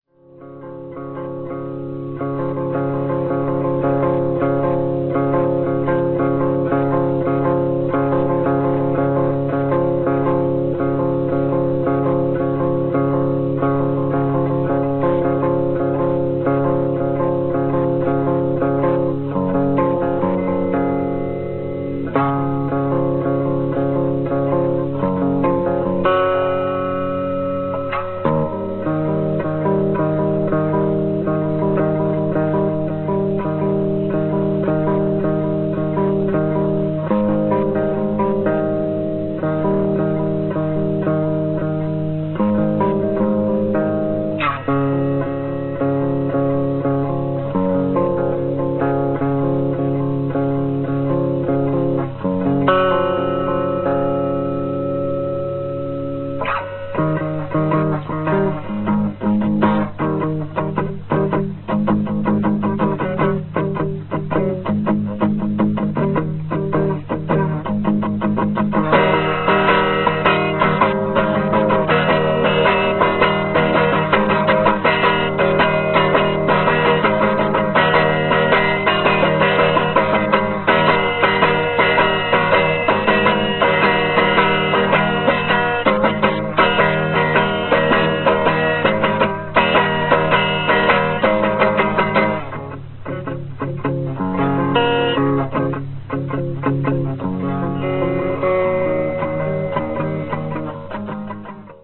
A little collage of song's fragment from band's practices: